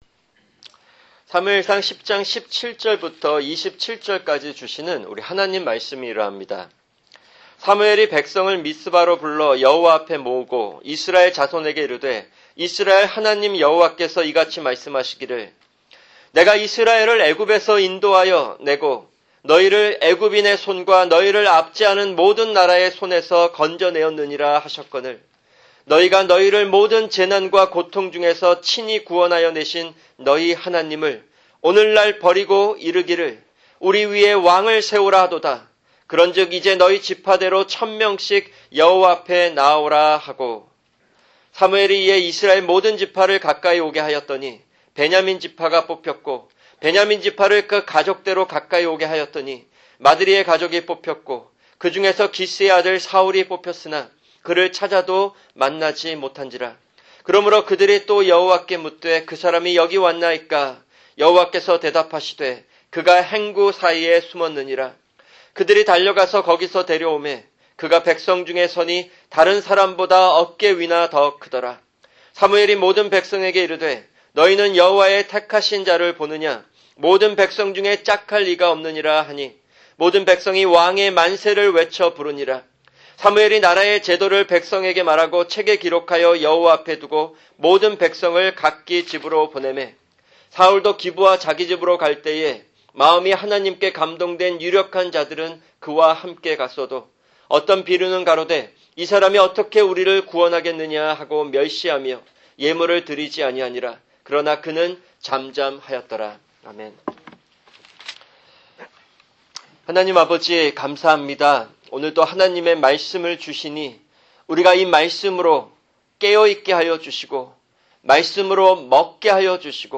[주일 설교] 사무엘상(27) 10:17-27(1)